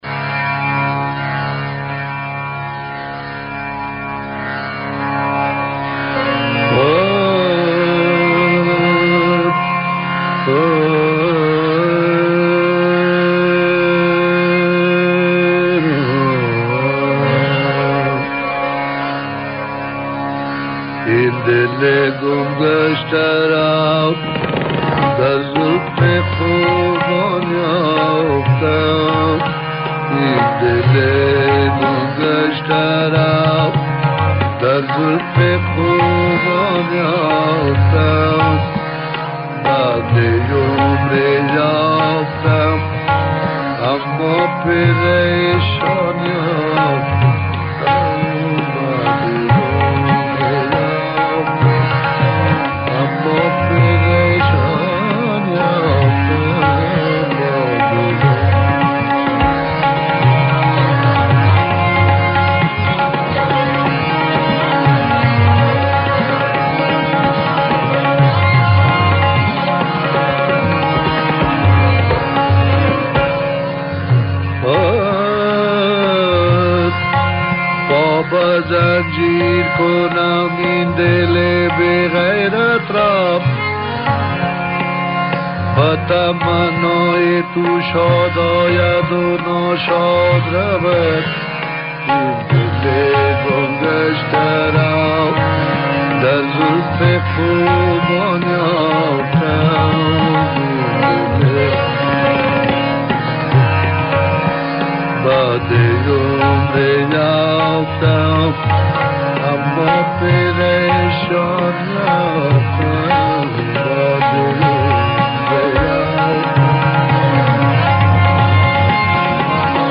نسخۀ زنجیر از نظر کیفیت صوت بهتر است.